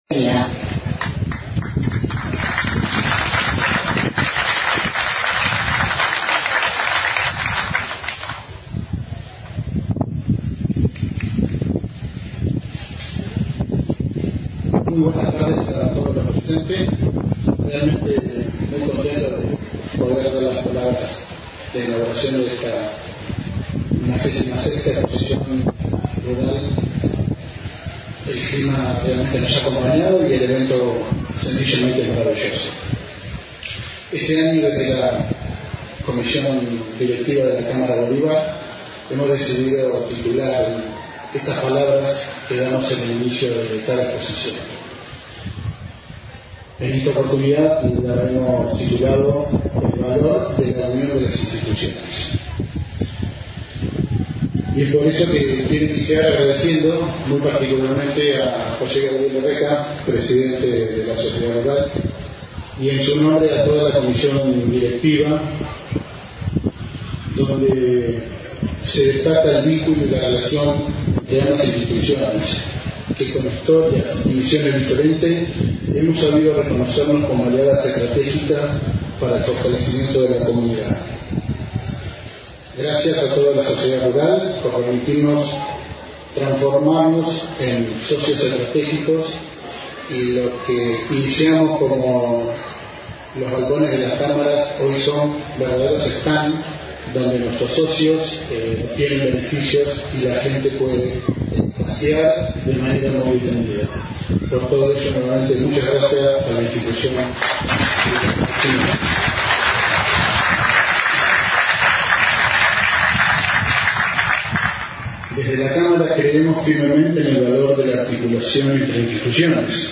Se Inauguró El Sector Comercial En La Exposición Rural de Bolívar 2025
Discursos: